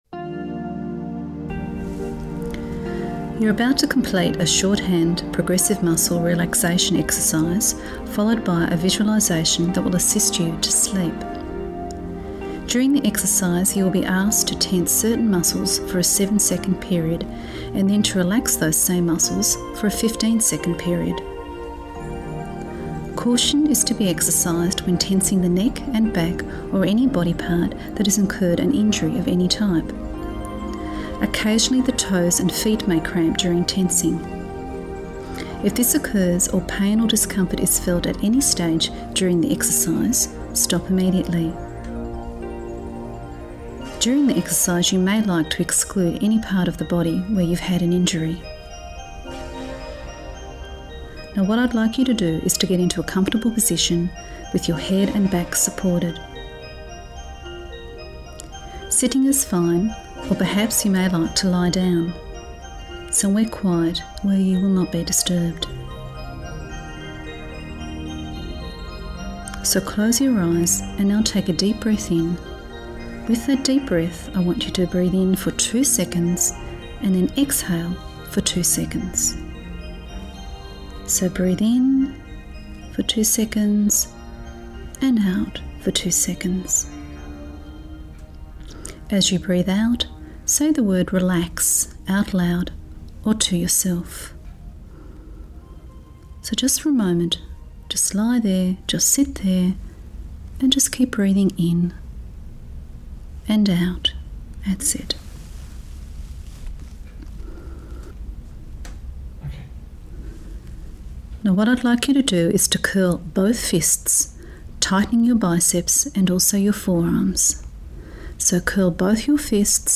Shorthand_Progressive_muscle_relaxation_Sleep_Visualisation.mp3